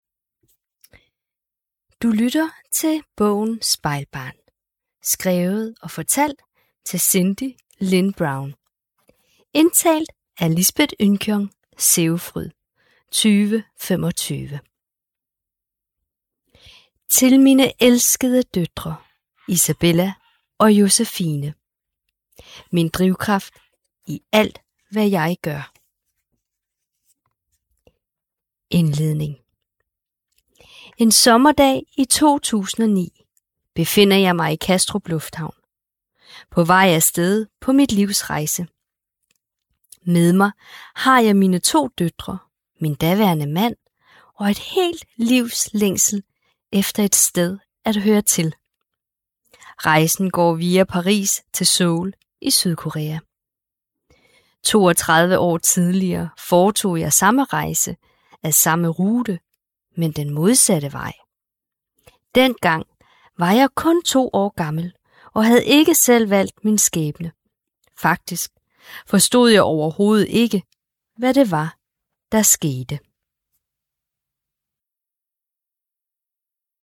Lydbog (zip_mp3)